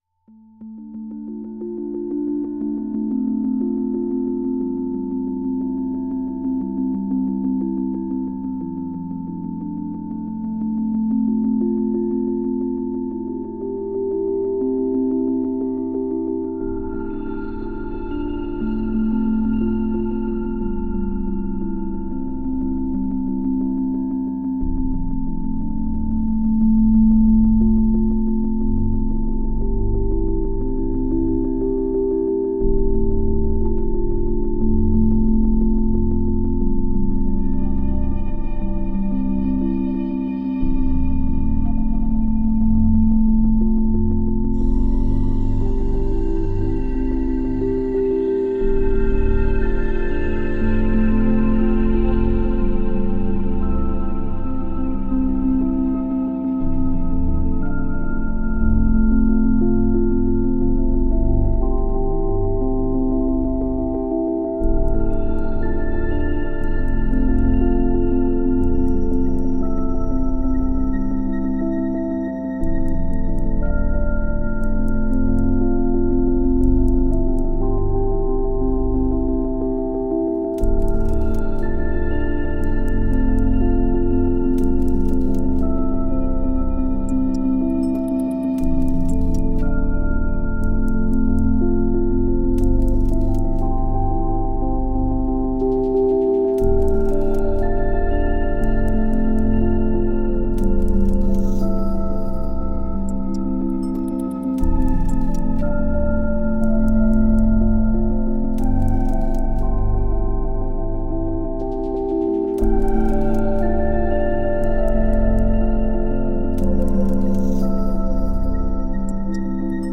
l’ensemble des ces fréquences 888Hz 88Hz 8Hz construit le pyramide de la prospérité